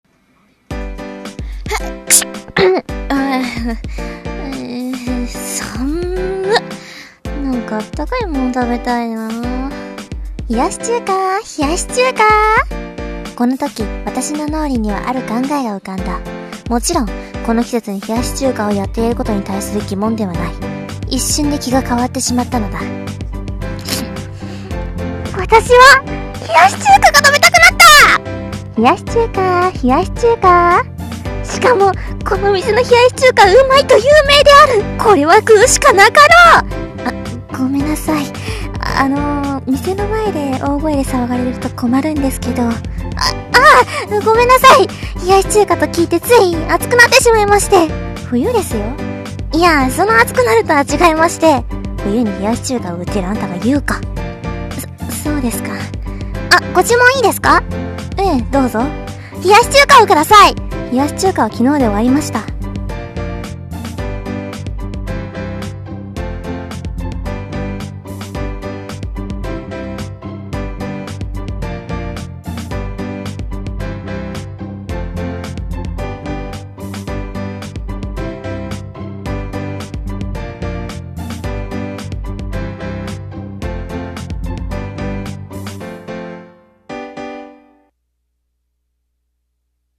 [ネタ 掛け合い声劇台本]